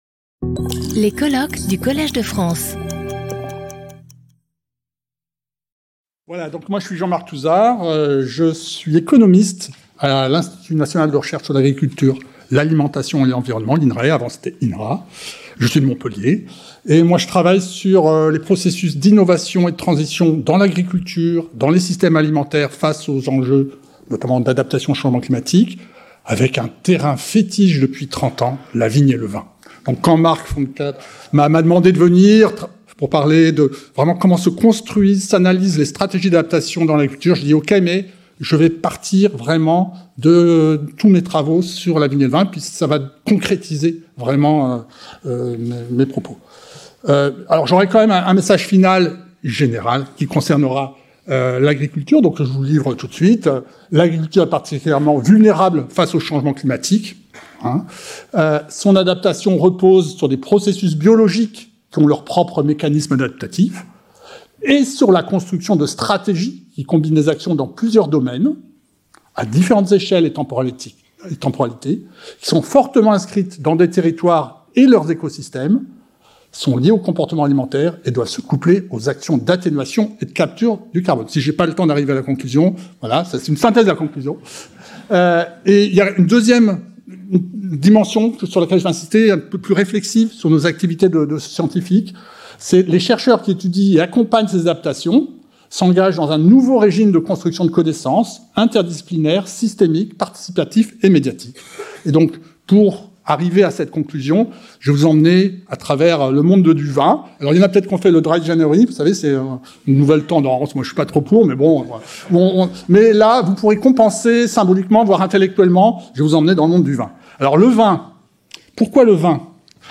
How to build adaptation strategies for agriculture? Lectures on vine and wine research | Collège de France